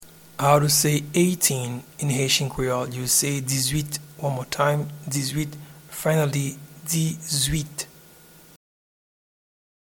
Pronunciation and Transcript:
Eighteen-in-Haitian-Creole-dizwit.mp3